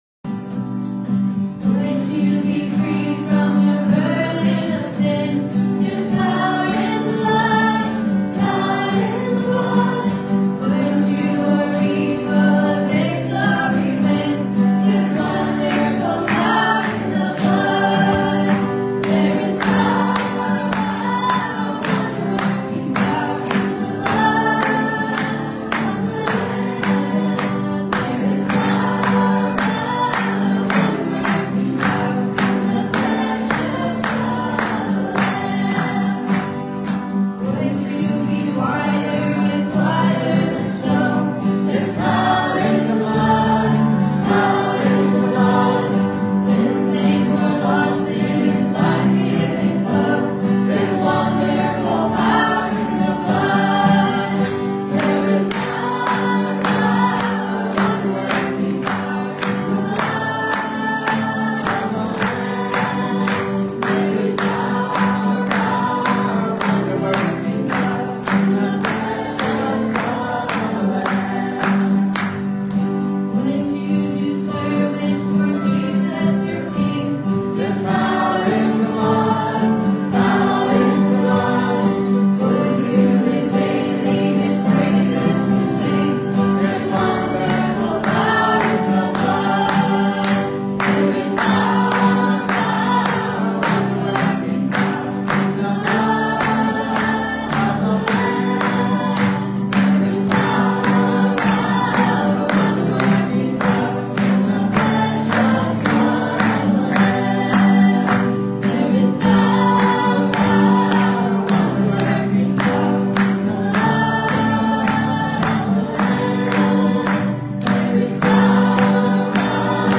Romans 9:19-30 Service Type: Sunday Morning « Christ the Stumbling Stone and the End of the Law Are God’s Ways Just?